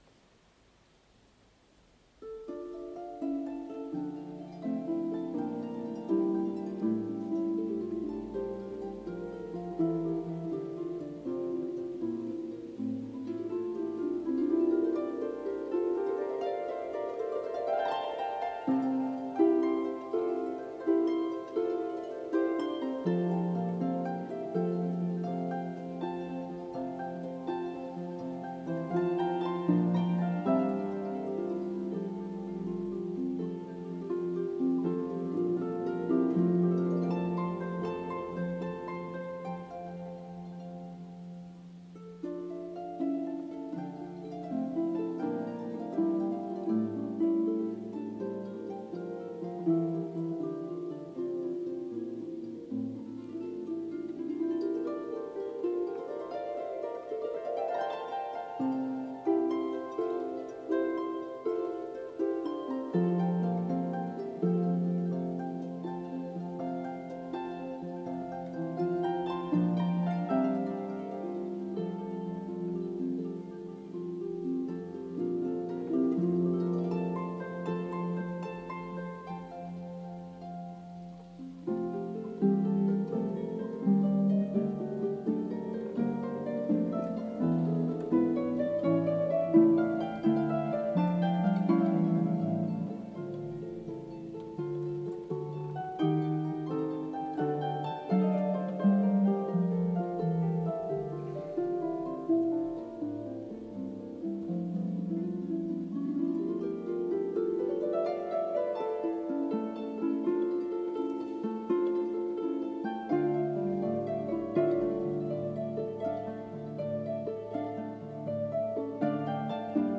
for two pedal harps